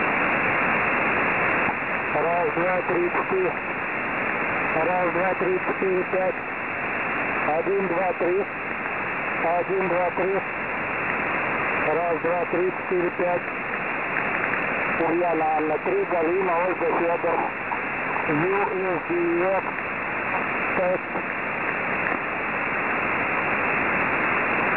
До того,как я благополучно убил свой usdx, получил на нем вот такое звучание на передачу.